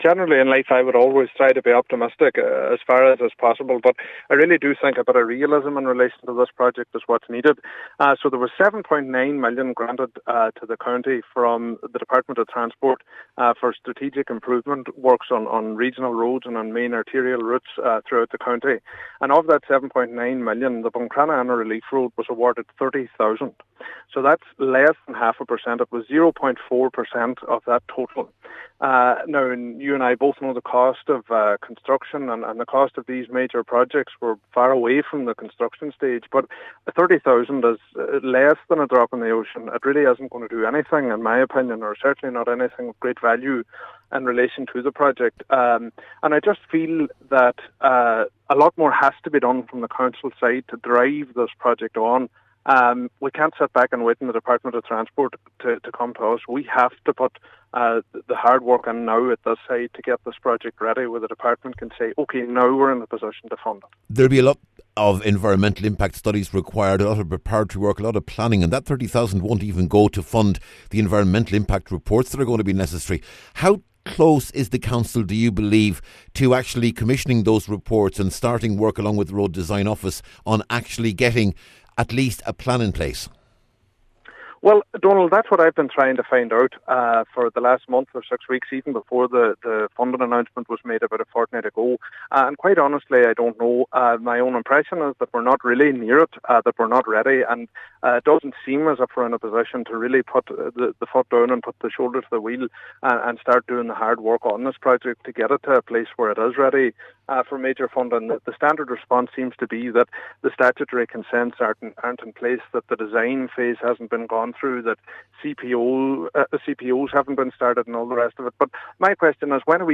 You can listen to a longer discussion with Cllr Bradley here –